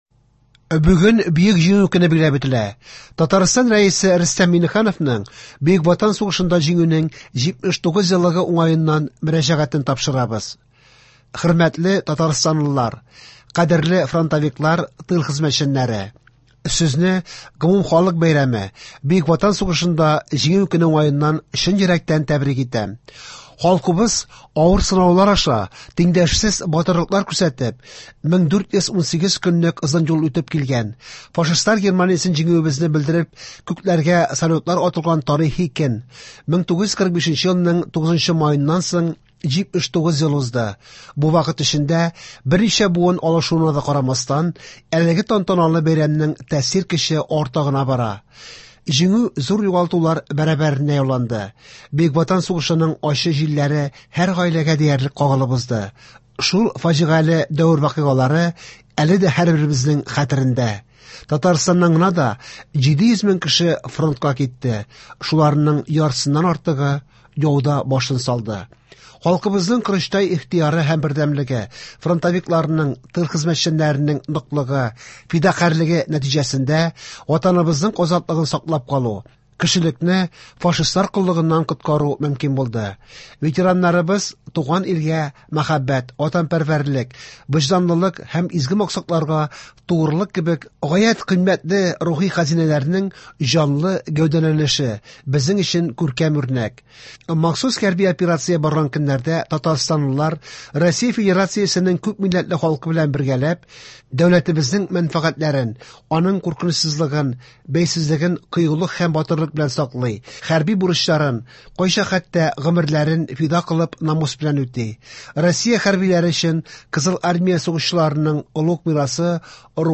Яңалыклар (09.05.24)